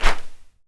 SWISH 2   -S.WAV